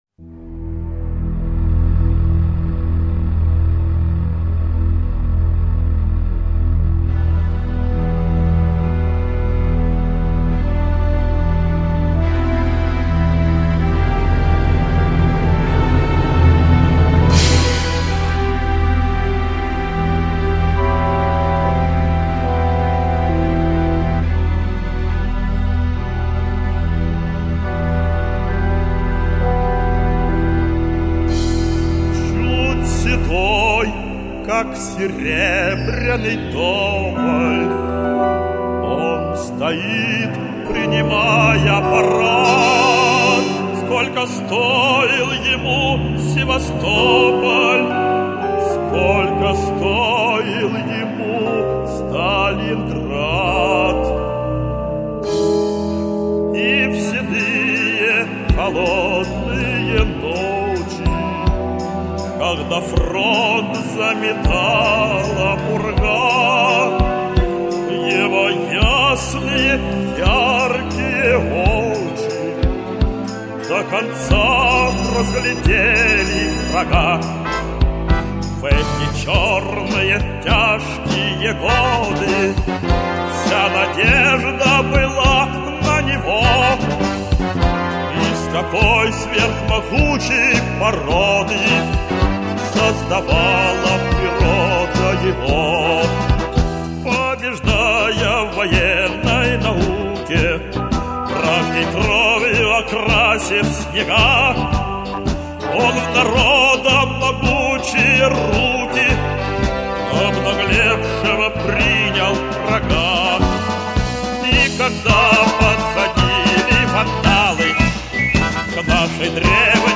Старая песня в новом исполнении.